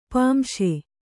♪ pāmśe